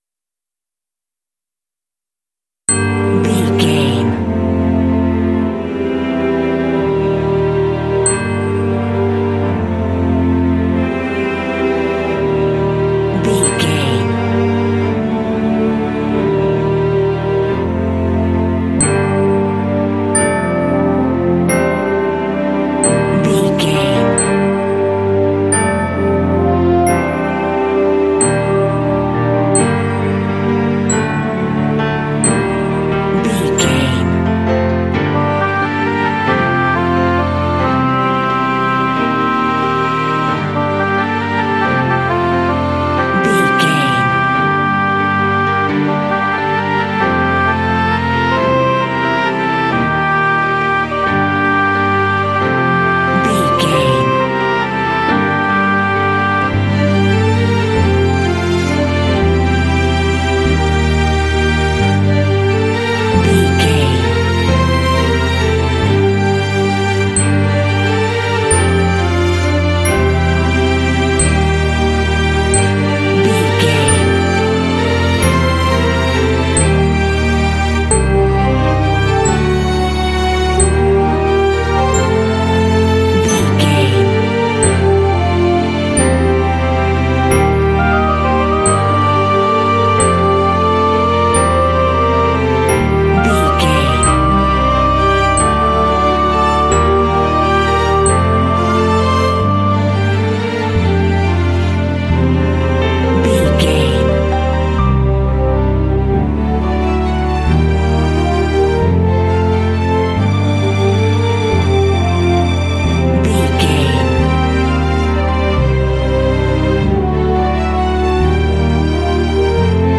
Uplifting
Aeolian/Minor
Slow
mystical
dreamy
ethereal
peaceful
horns
oboe
piano
bass guitar
cinematic